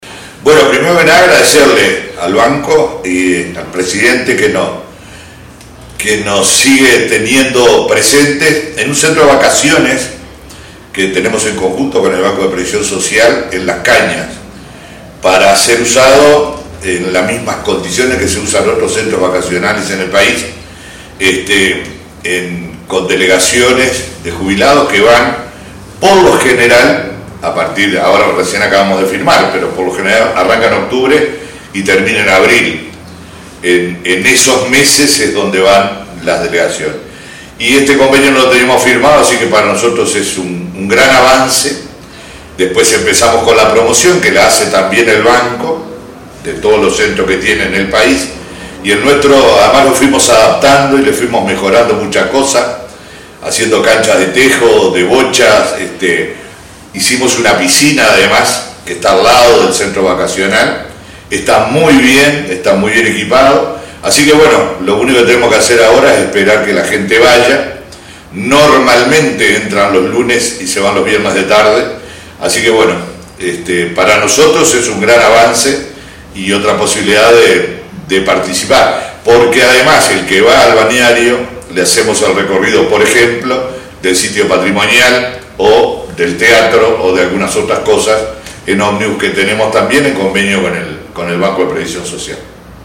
Declaraciones del intendente de Río Negro, Omar Lafluf